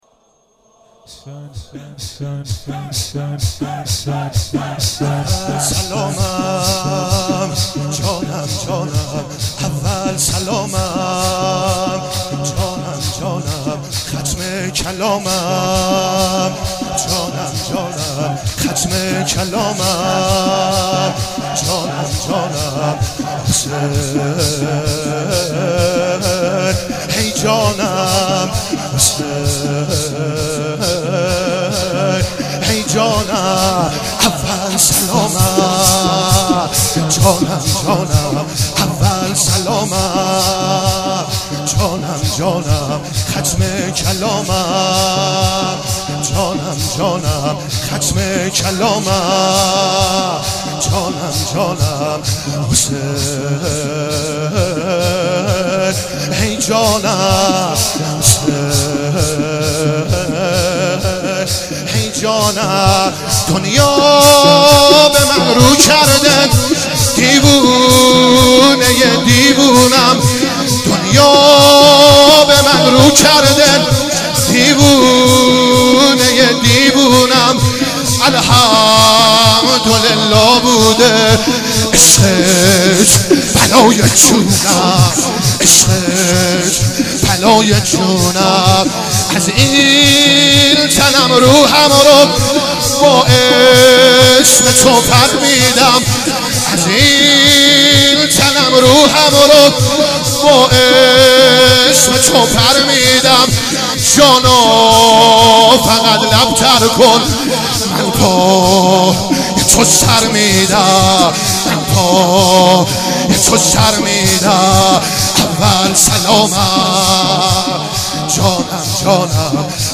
06 heiate alamdar mashhad.mp3